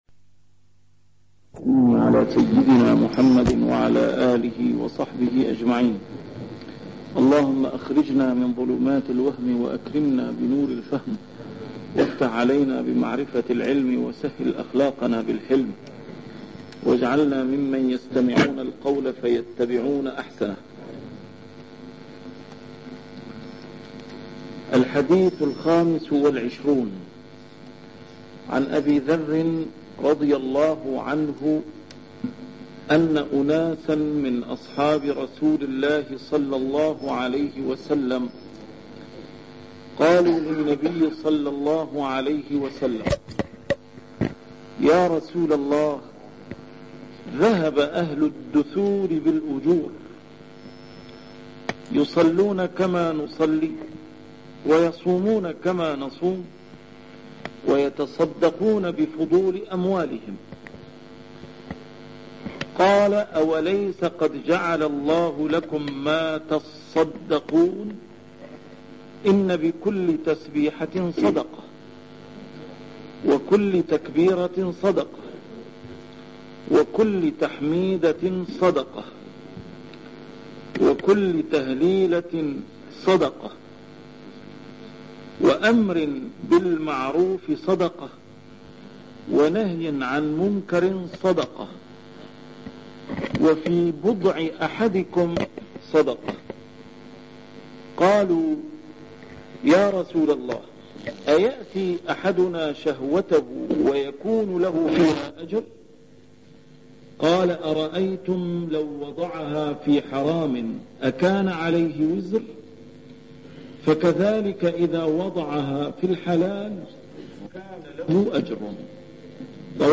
A MARTYR SCHOLAR: IMAM MUHAMMAD SAEED RAMADAN AL-BOUTI - الدروس العلمية - شرح الأحاديث الأربعين النووية - بداية شرح الحديث الخامس والعشرون: حديث أبي ذر الغفاري (ذهب أهل الدثور بالأجور) 83